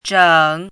“拯”读音
zhěng
拯字注音：ㄓㄥˇ
国际音标：tʂəŋ˨˩˦